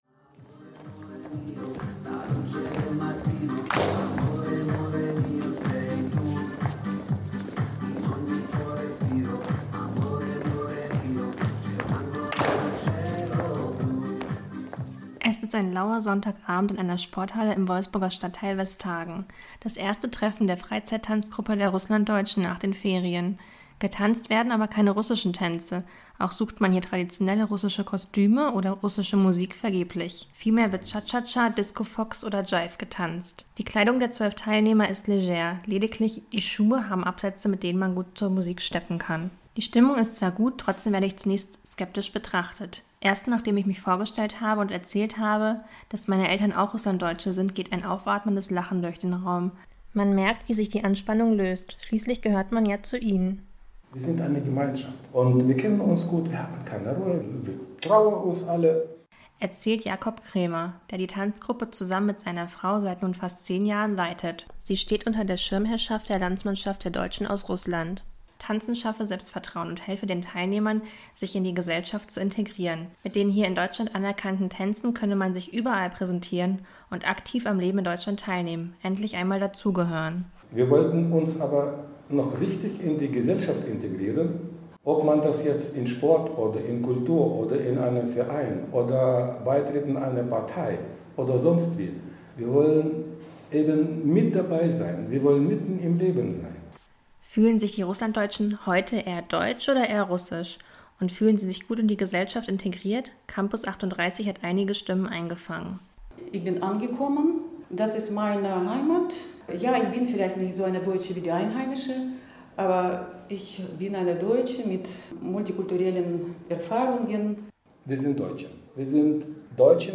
Fühlen sie sich in der Gesellschaft integriert? Campus38 zu Gast bei einer Wolfsburger Tanzgruppe für Russlanddeutsche.